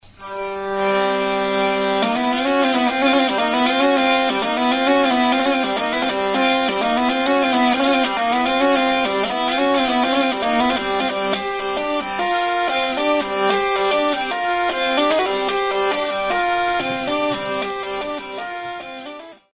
La vielle est conçue comme instrument amplifié sans aucune résonnance propre. Le son est capté par des senseurs electromagnétiques (donc cordes en métal obligatoires).
Le son dépend bien entendu de l'amplification du signal des capteurs et peut être varier au moyen d'un égalisateur.